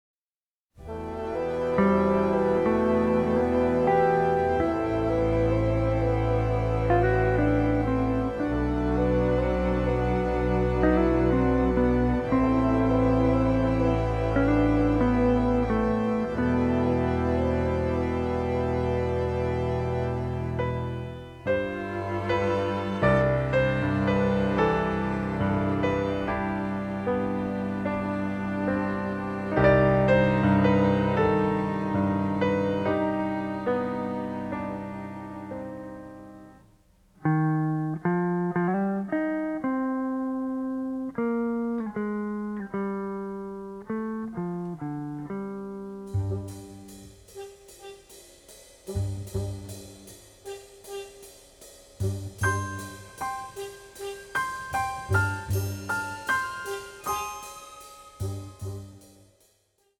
The music is descriptive, romantic and funny
All tracks stereo, except * mono